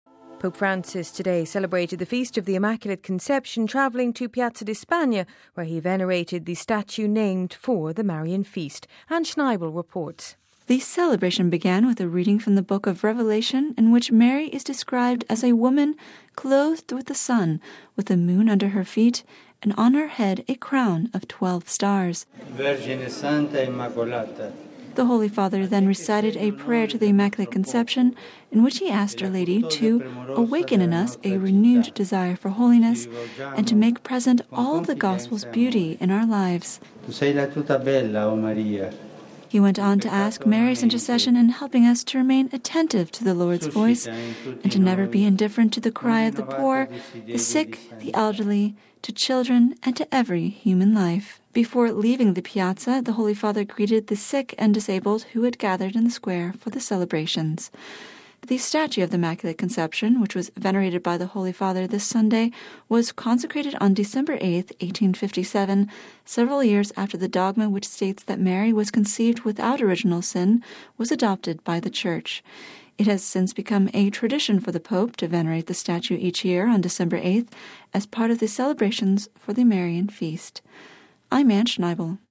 (Vatican Radio) Following a tradition laid out by his predecessors, Pope Francis celebrated the Feast of the Immaculate Conception by travelling to Piazza di Spagna where he venerated the statue named for the Marian Feast.